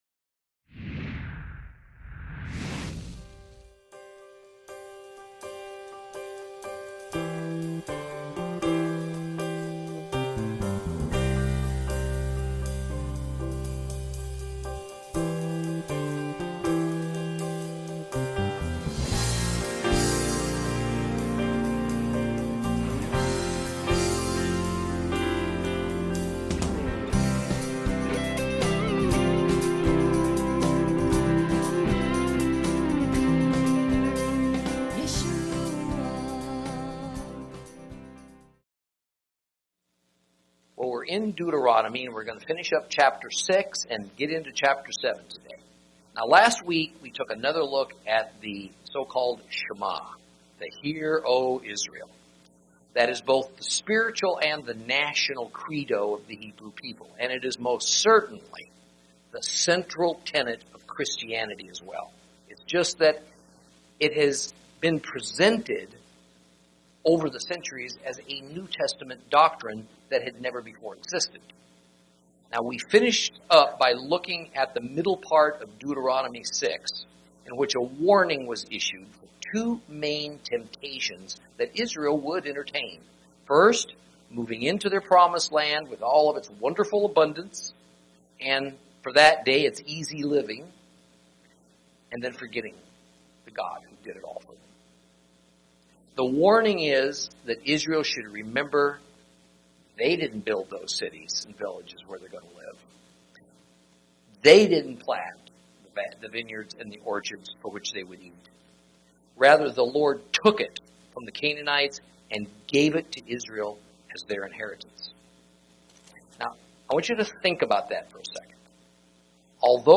Lesson 10 – Deuteronomy 6 & 7